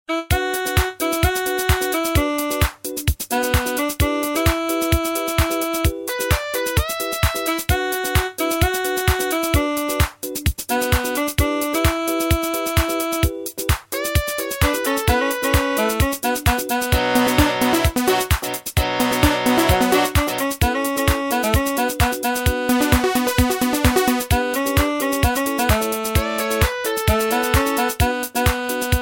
Bollywood
cool , nice , instrument ,